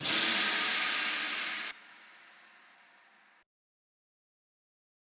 Dirty Crash.wav